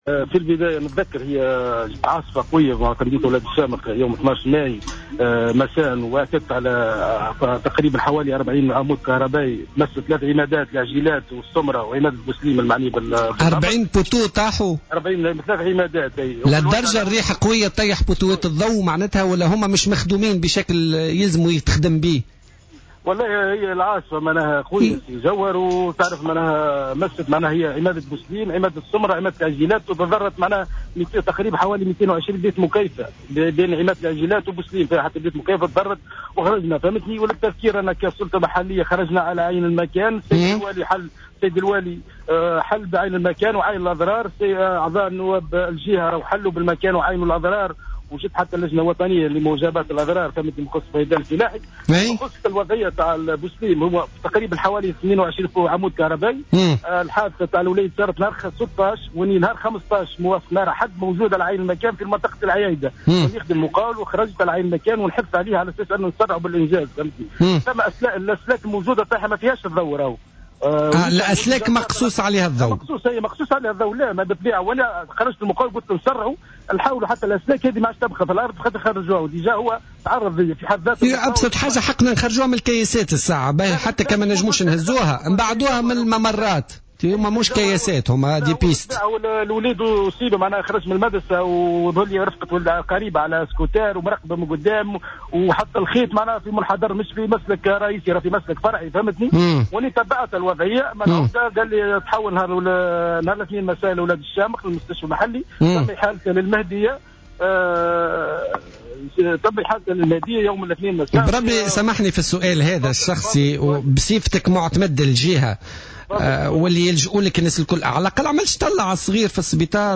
أكد معتمد أولاد الشامخ من ولاية المهدية، عيسى موسى في مداخلة له اليوم في برنامج "بوليتيكا" أنه يتابع عن كثب الحادثة التي تعرّض لها طفل في الجهة وتمثلت في إصابته بعد تعثره بسلك كهربائي كان ملقى على الطريق إثر الرياح القوية التي شهدتها الجهة منذ أسبوع.